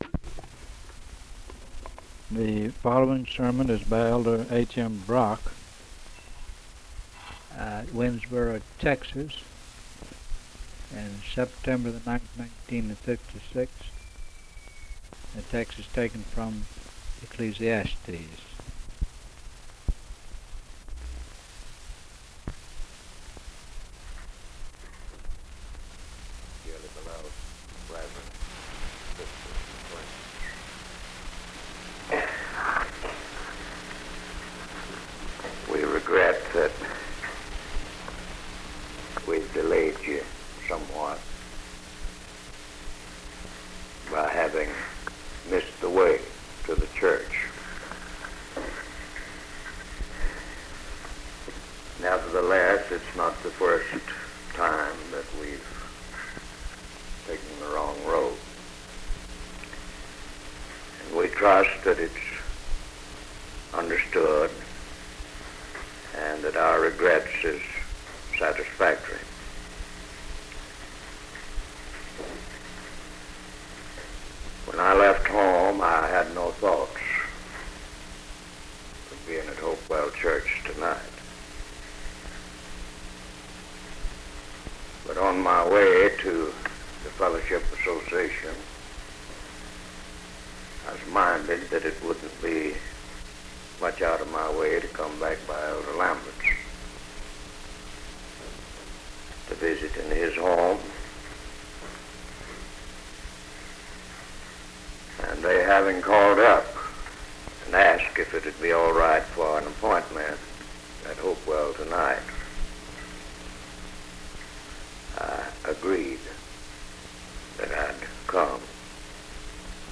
From a collection of sermons